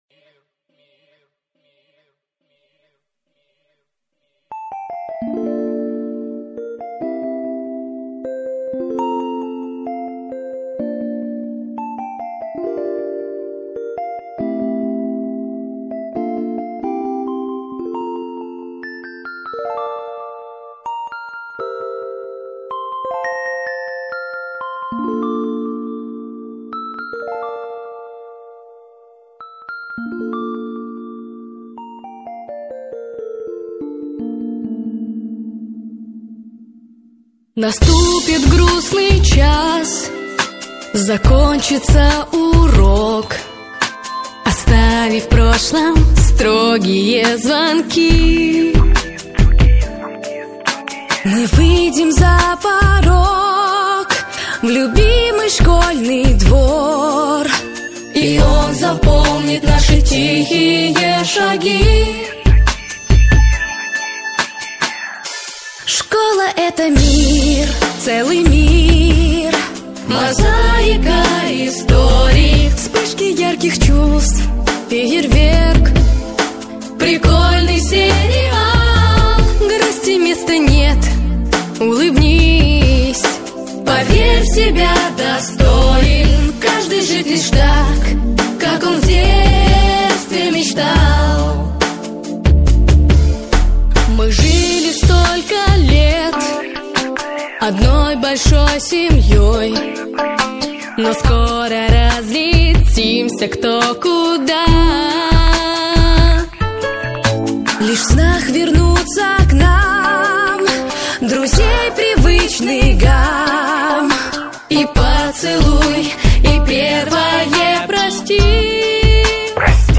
Танцевальная